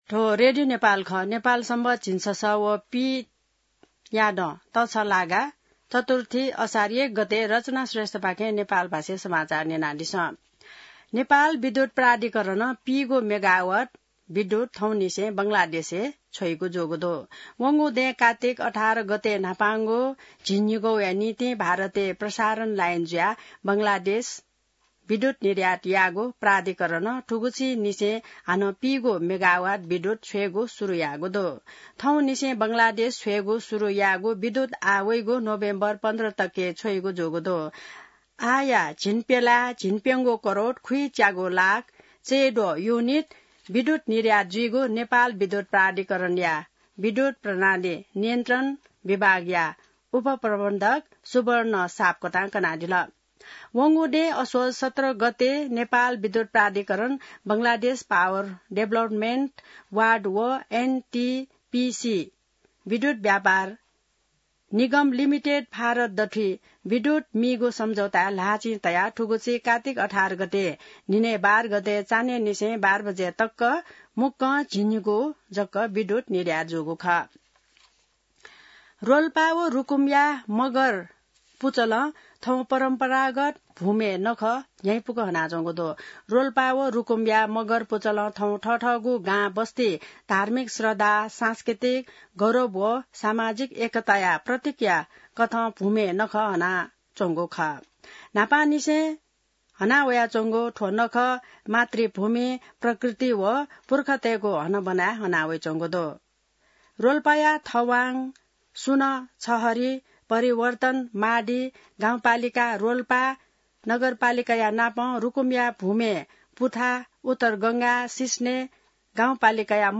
नेपाल भाषामा समाचार : १ असार , २०८२